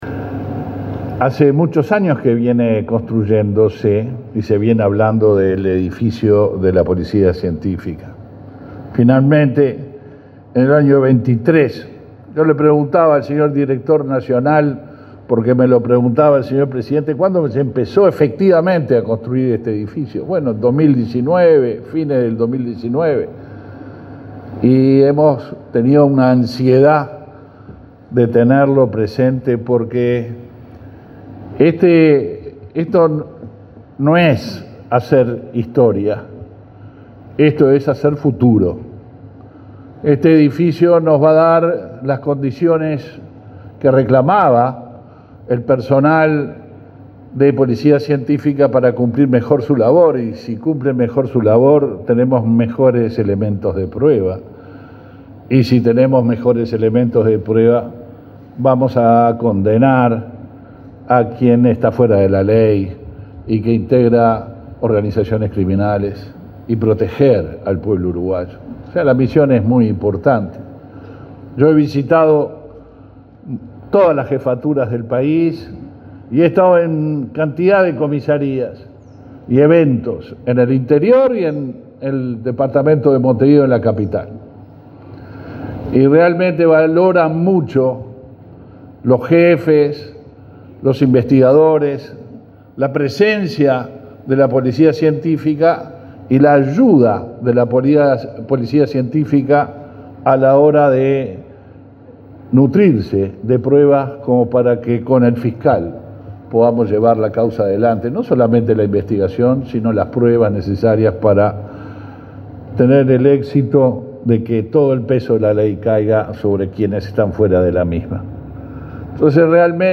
Palabras del ministro del Interior, Luis Alberto Heber
Este viernes 29, el ministro del Interior, Luis Alberto Heber, participó en la inauguración de la nueva sede de la Policía Científica, en Montevideo.